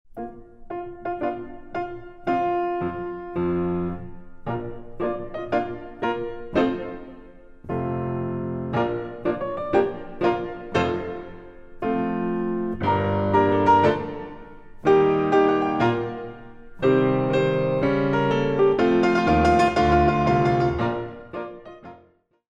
Compositions for Ballet Class
The CD is beautifully recorded on a Steinway piano.